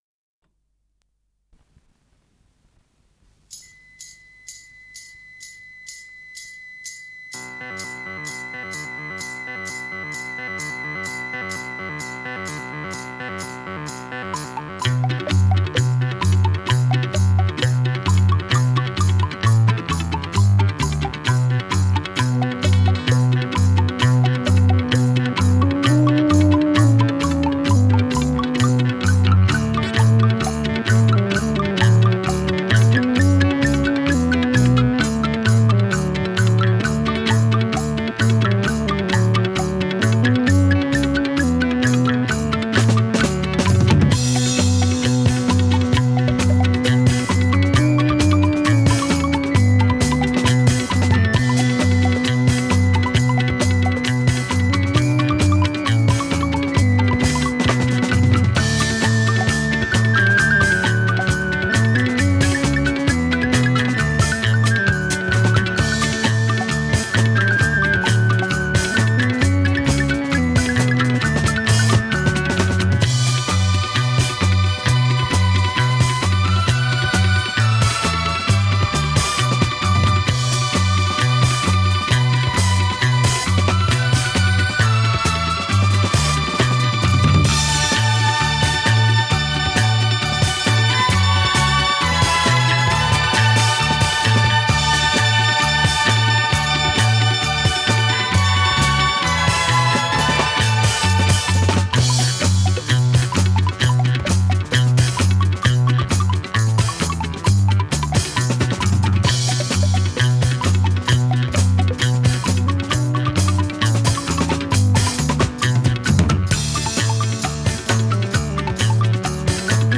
ПодзаголовокСи минор
Скорость ленты38 см/с
ВариантДубль моно